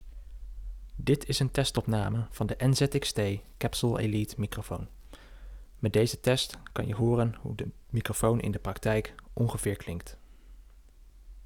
Opnamekwaliteit microfoon
Zo integreert de Capsule Elite een effectievere ingebouwde DSP (Digital Signal Processor) die sis- en plofklanken subtiel corrigeert.
Gecombineerd met een erg goede native ruisonderdrukking en een redelijk gebalanceerde equalizer ‘out of the box’, heeft de Capsule Elite best wel indruk op mij gemaakt.
NZXT Capsule Elite - 100% volume